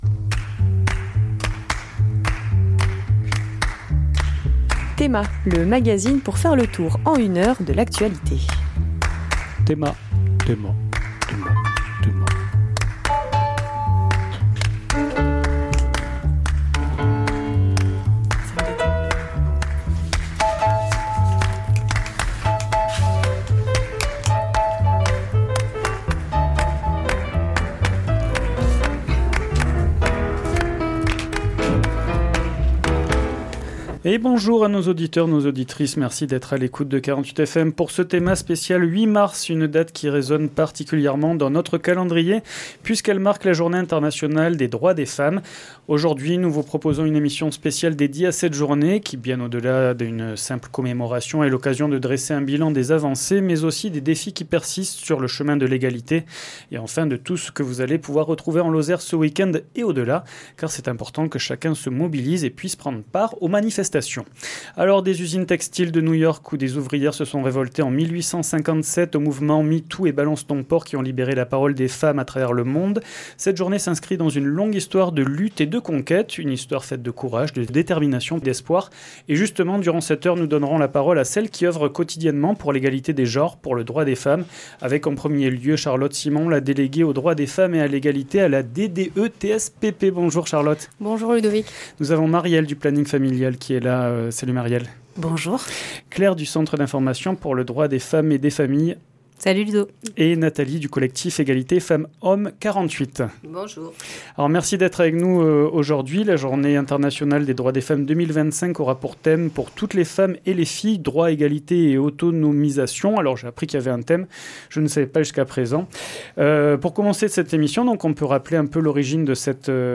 Discussion autour du 8 mars – Journée internationale des droits des femmes